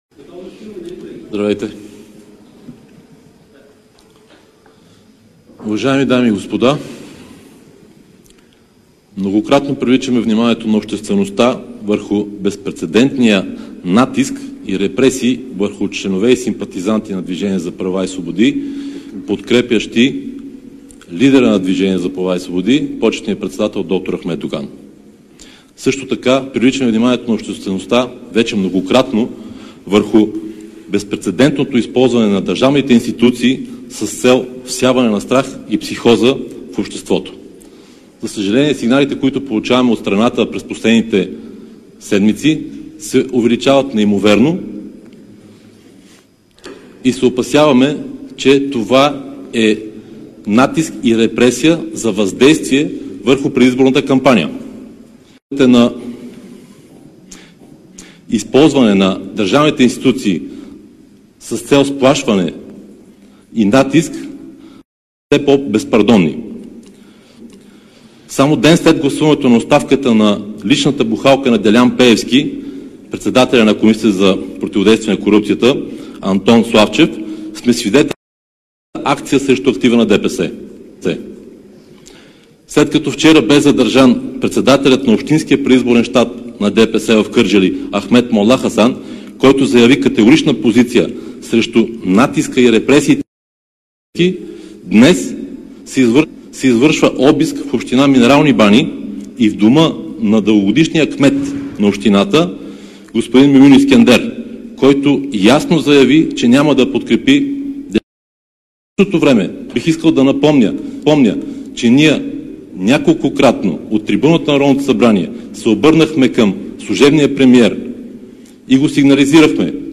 10.05 - Брифинг на председателя на Възраждане Костадин Костадинов за гласуването на оставката на председателя на КПКОНПИ. - директно от мястото на събитието (Народното събрание)